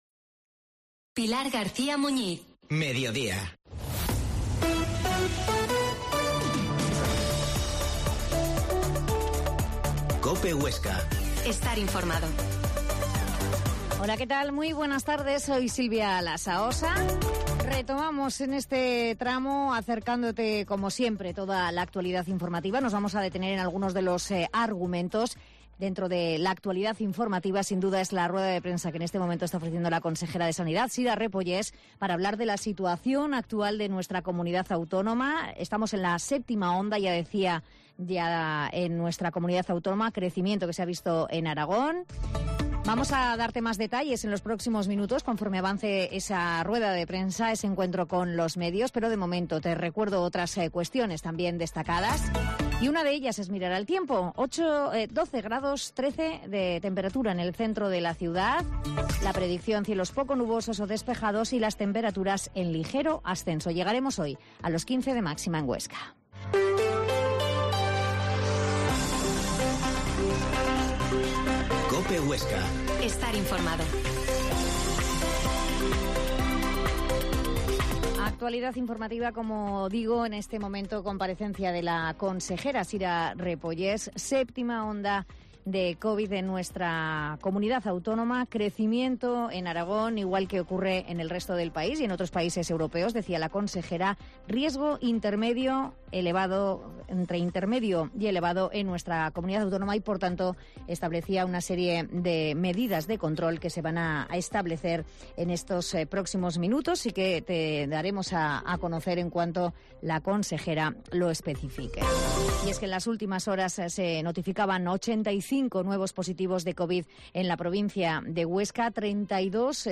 La Mañana en COPE Huesca - Informativo local Mediodía en Cope Huesca 13,20h.